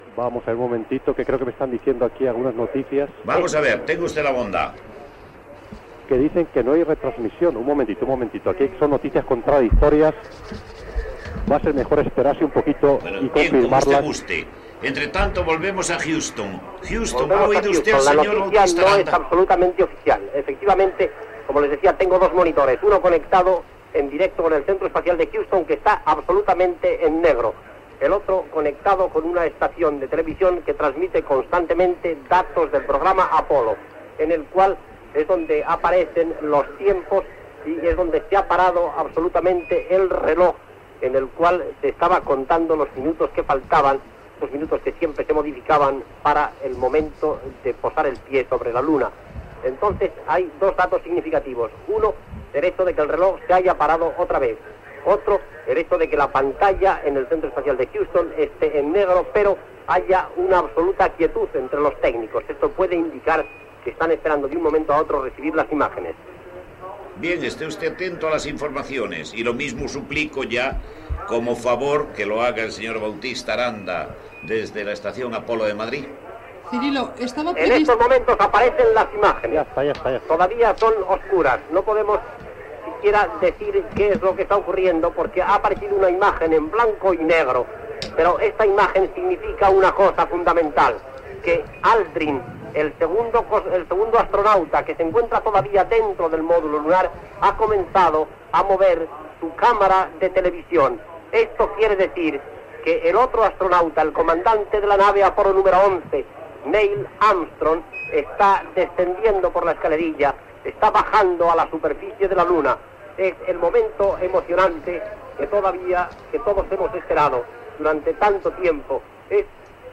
f8264f78d8c7d527b8c6c048c1dd76ece61fc46c.mp3 Títol Radio Nacional de España Emissora Radio Nacional de España Barcelona Cadena RNE Titularitat Pública estatal Descripció Transmissió, des d'Huston (EE.UU.), del moment que l'astronauta de la NASA Neil Armstrong, comandant de l'Apol·lo 11, va trepitjar la superfície de la Lluna i quan minuts després ho va fer el segon astronauta Edwin Eugene Aldrin .
Gènere radiofònic Informatiu